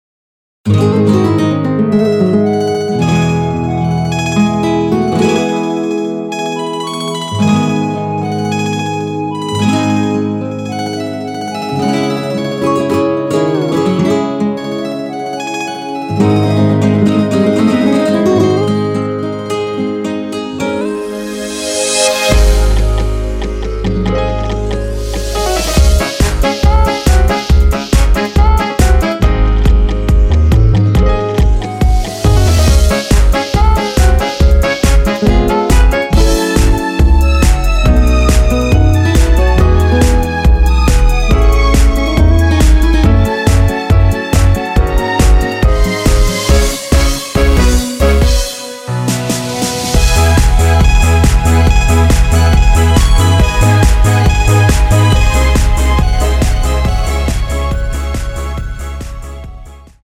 원키에서(-1)내린 멜로디 포함된 MR입니다.
Dbm
멜로디 MR이라고 합니다.
앞부분30초, 뒷부분30초씩 편집해서 올려 드리고 있습니다.
중간에 음이 끈어지고 다시 나오는 이유는